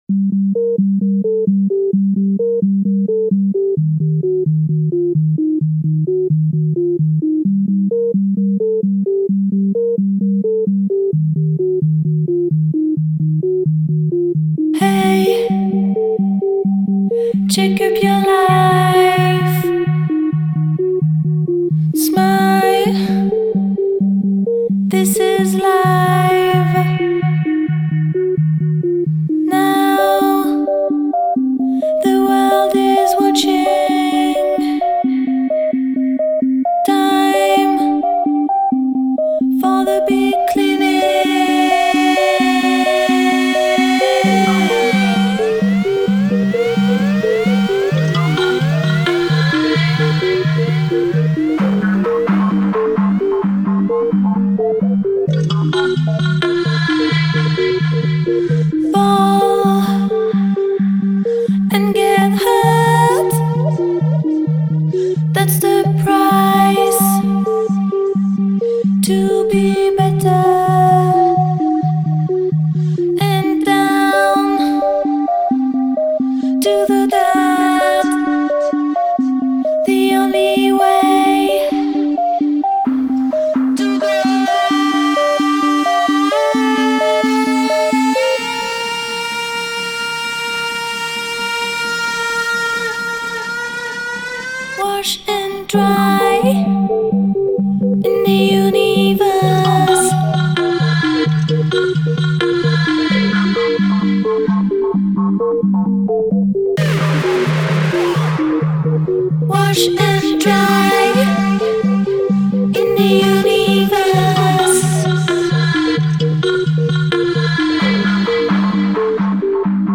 strashnaya_muzyka_2_strashnaya_muzyka.mp3